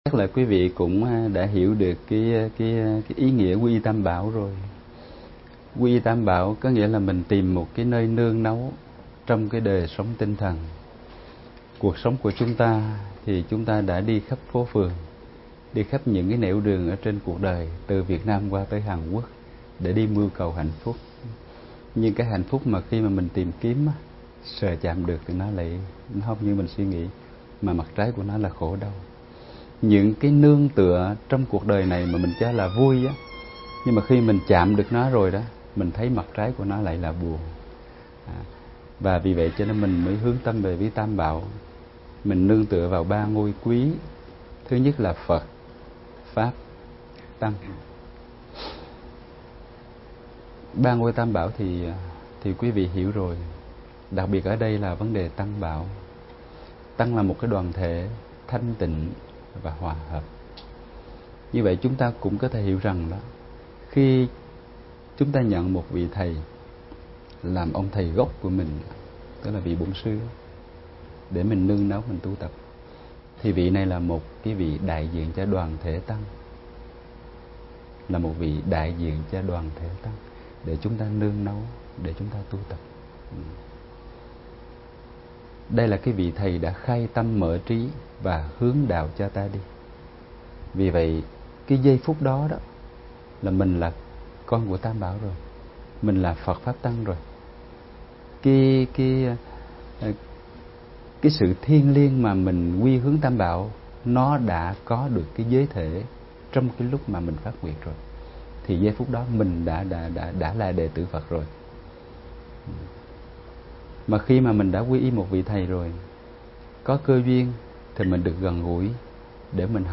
Vấn đáp: Quy y Tam Bảo